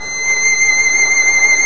Index of /90_sSampleCDs/Roland - String Master Series/STR_Violin 1 vb/STR_Vln1 _ marc